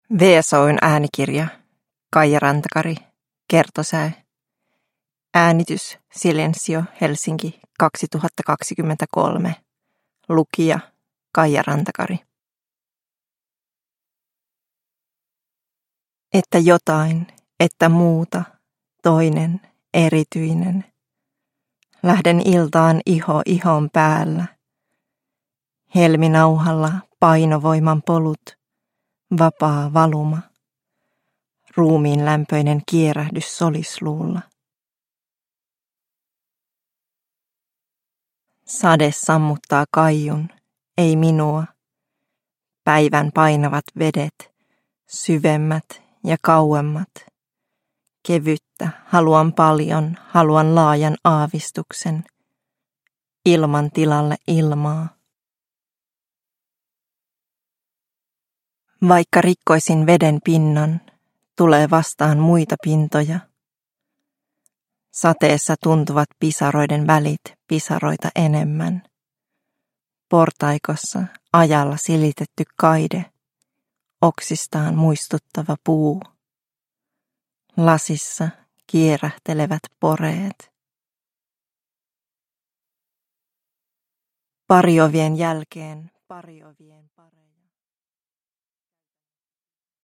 Kertosäe – Ljudbok – Laddas ner